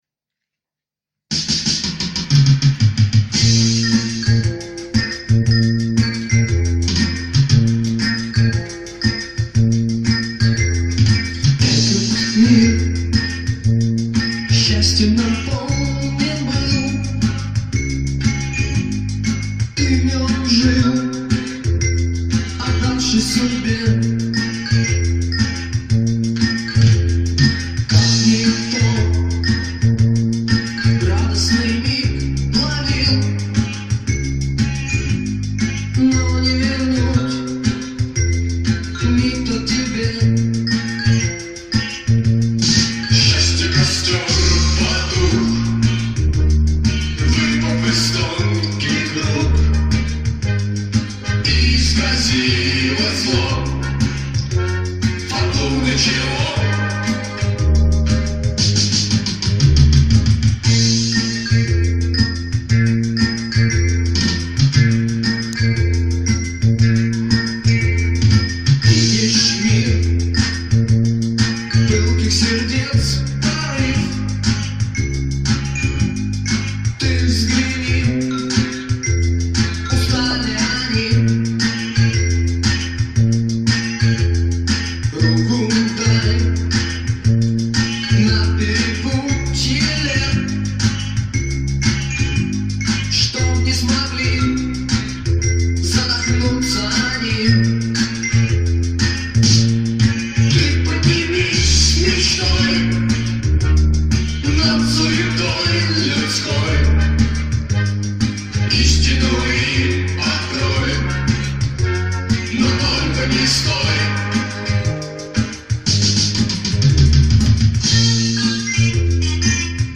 Всё сыграно на синтезаторе/компьютере.